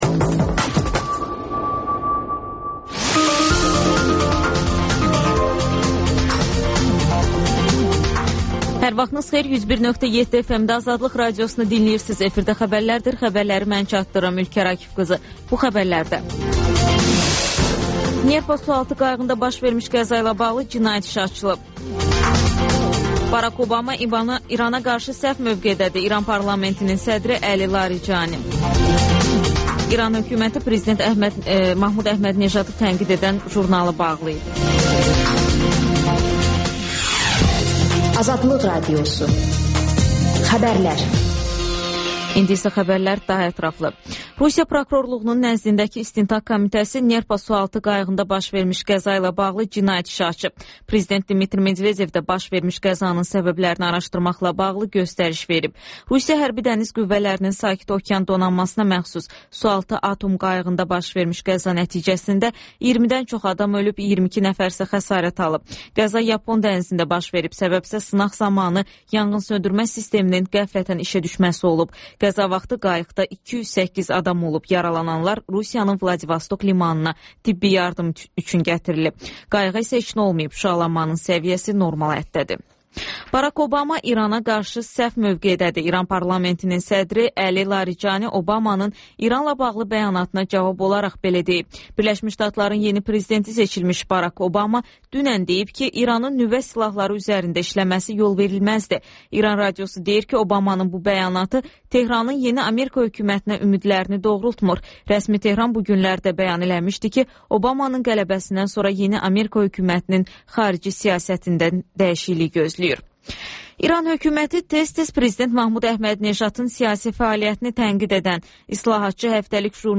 Xəbərlər, QAYNAR XƏTT: Dinləyici şikayətləri əsasında hazırlanmış veriliş, sonda 14-24